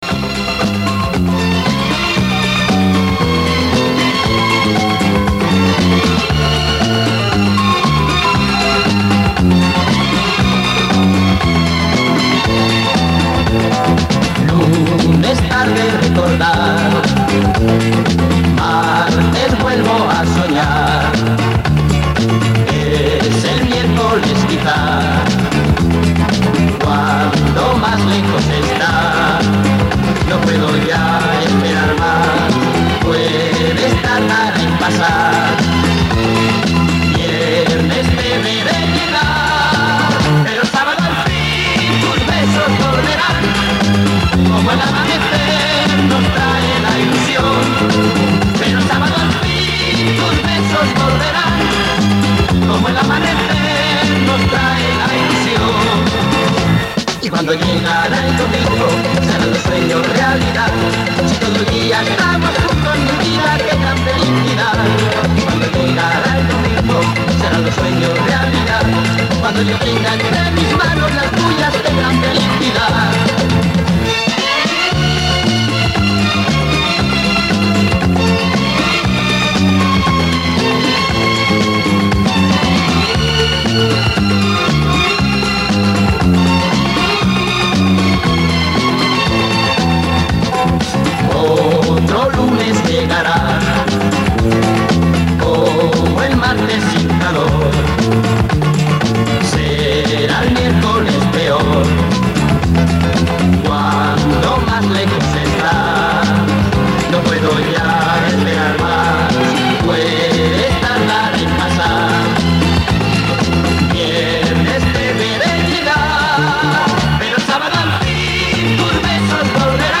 Ребята, добрый времени суток! Наткнулся на веселую песенку в исполнении неизвестного испаноязычного певца - очень понравилось- немного подправил звук.